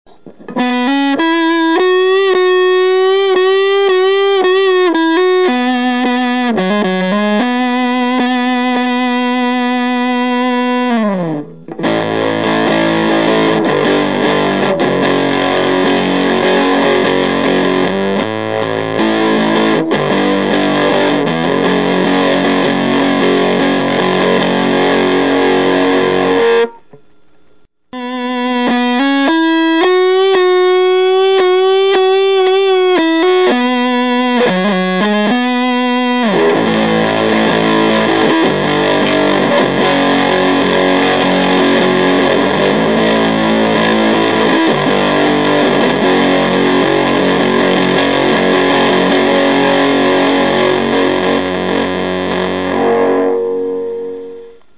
Tonebender Professional MK II clips
The first bit (some single notes, then chords) is with Fuzz set at 50%, the second bit has Fuzz set at 100%.
How the clips were recorded:  Pretty guitar - effect - LM386 amp - 2x12 open cab